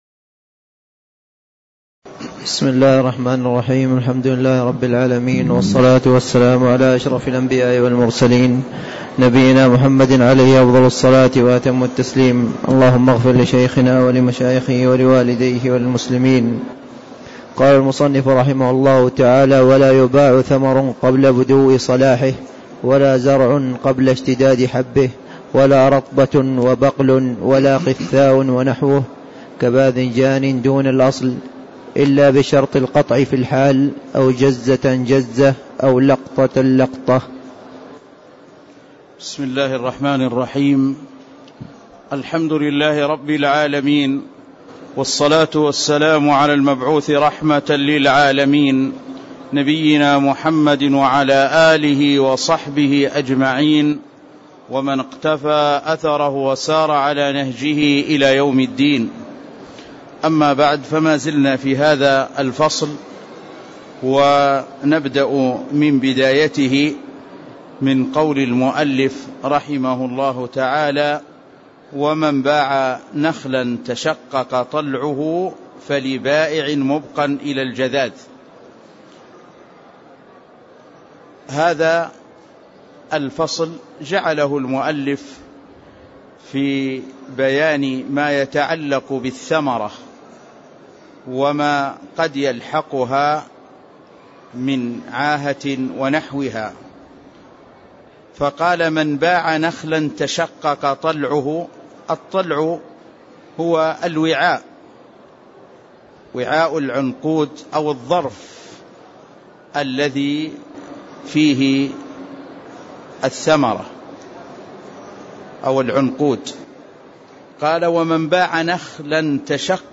تاريخ النشر ٢٤ رجب ١٤٣٦ هـ المكان: المسجد النبوي الشيخ